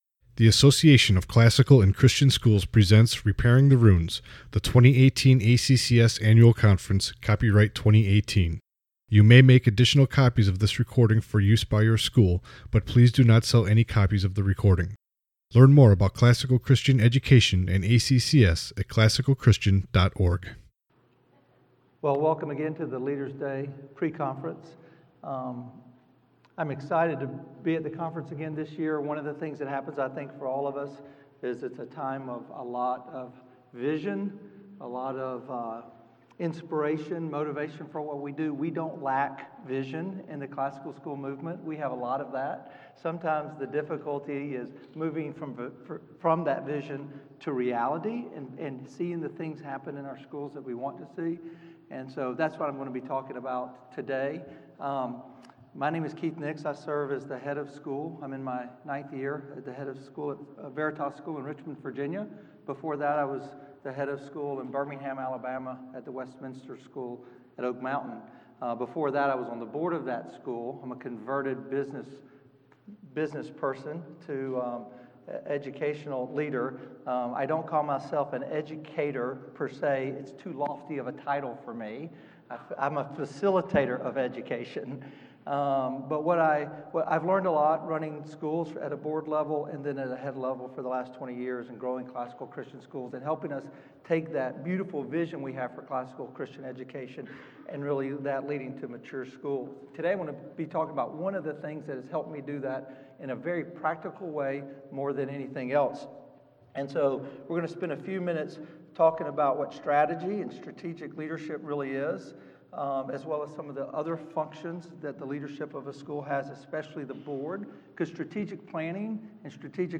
2018 Leaders Day Talk | 48:14 | Fundraising & Development, Leadership & Strategic